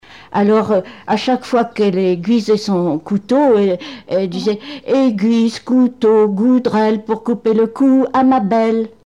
formulette enfantine : amusette
Témoignages et chansons
Pièce musicale inédite